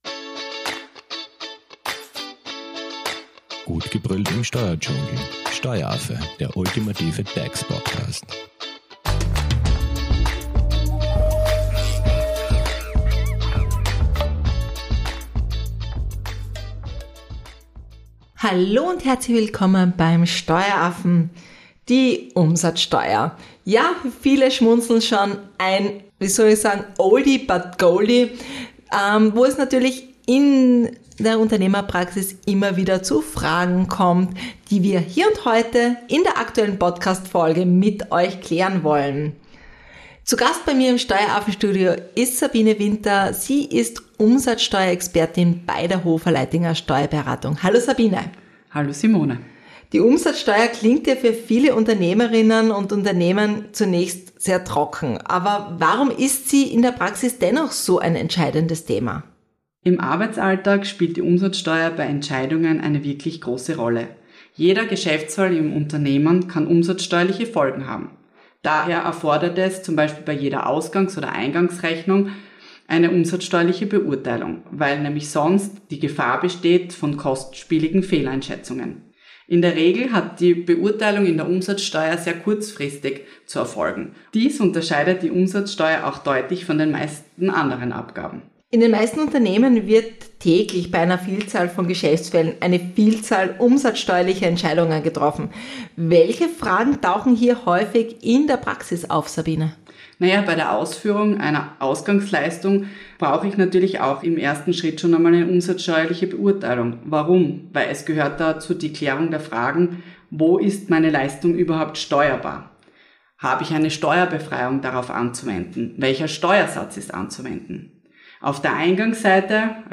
Zu Gast im Steueraffen-Studio ist Umsatzsteuerexpertin